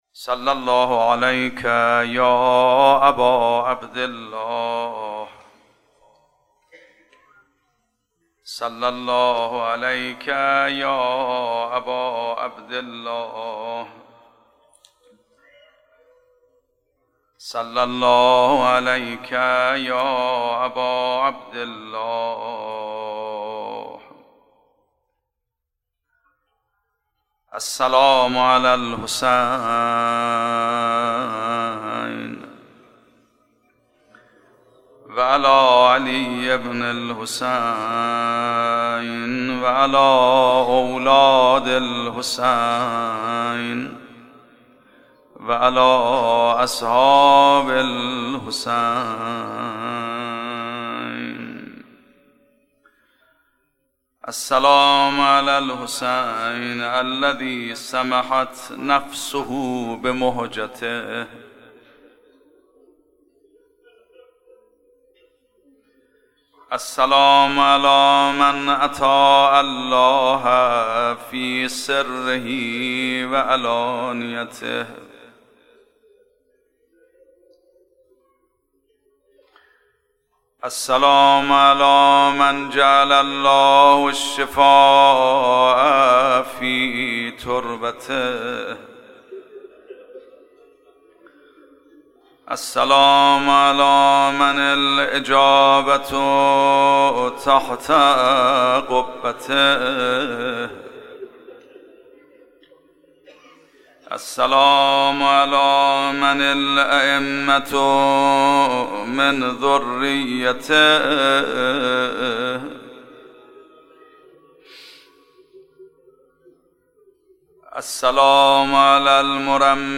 شب چهارم محرم 96 - هیئت ثار الله - روضه
روضه حجت الاسلام میرباقری